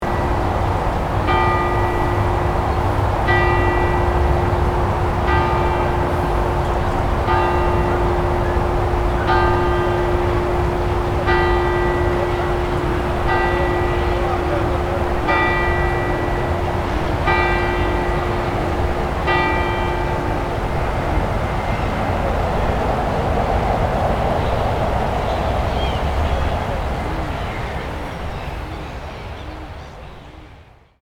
Campane e torrente - Città Metropolitana di Torino...
Rumore
Rumore Campane e torrente Campane ed avifauna con sottofondo di torrente< Ivrea, lungo la Dora Baltea
Microfoni binaurali stereo SOUNDMAN OKM II-K / Registratore ZOOM H4n
Campane-e-torrente.mp3